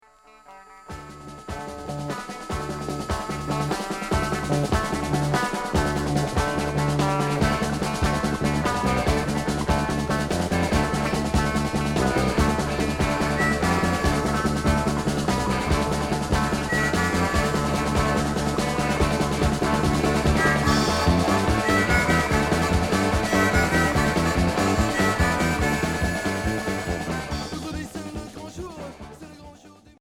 Punk rock Unique 45t retour à l'accueil